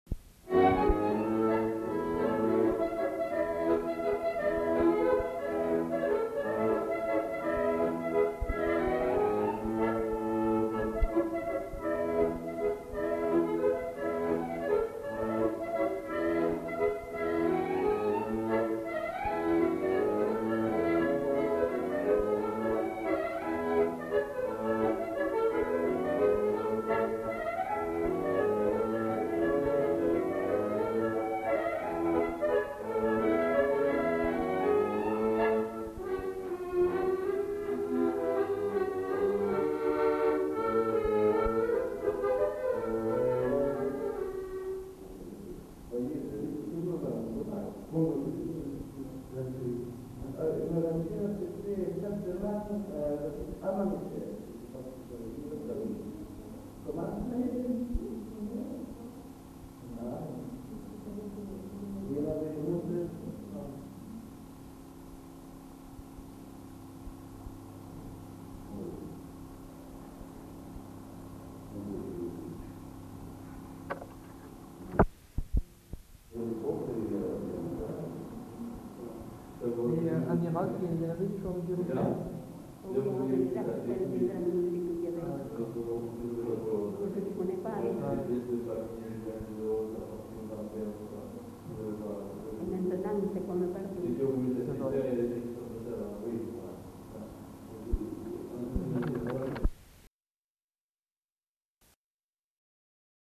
Aire culturelle : Néracais
Lieu : Moncaut
Genre : morceau instrumental
Instrument de musique : accordéon chromatique
Danse : java